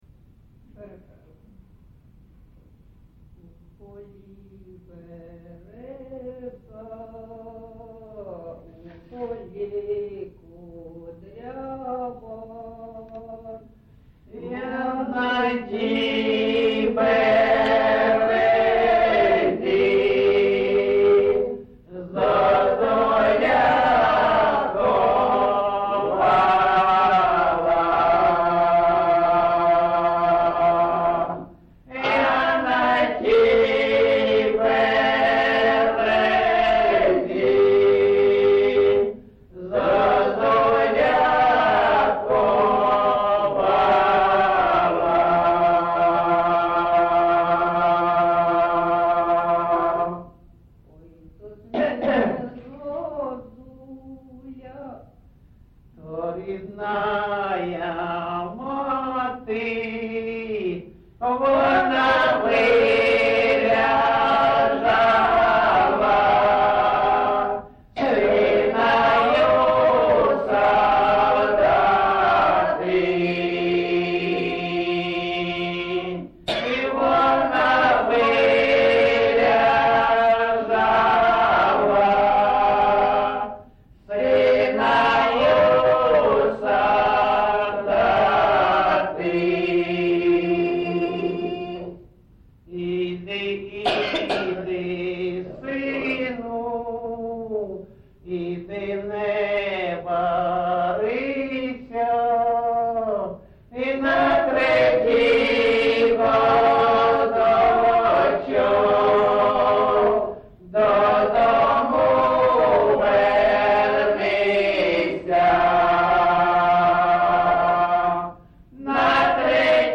ЖанрПісні з особистого та родинного життя, Балади
Місце записус. Маринівка, Шахтарський (Горлівський) район, Донецька обл., Україна, Слобожанщина